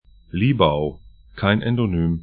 Libau 'li:bau Liepāja 'li:əpa:ja lv Stadt / town 56°31'N, 21°01'E